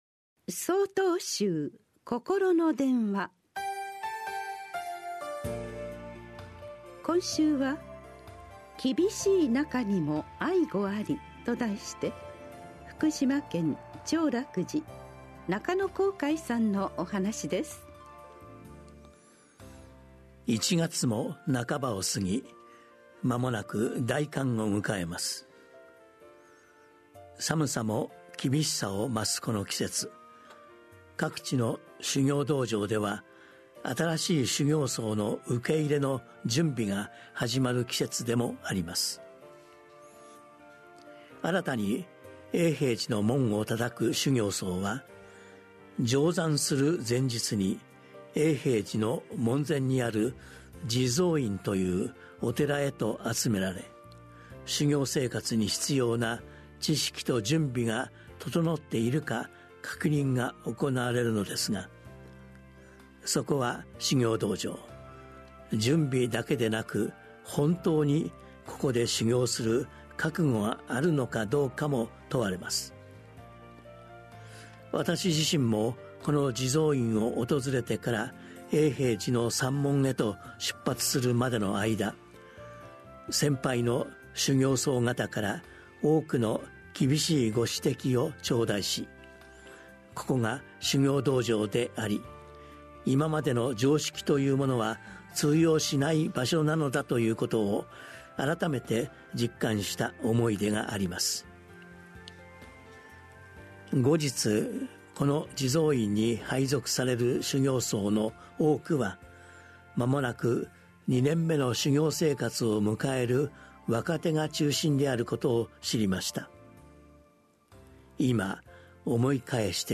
心の電話（テレホン法話）1/21公開『厳しい中にも愛語あり』 | 曹洞宗 曹洞禅ネット SOTOZEN-NET 公式ページ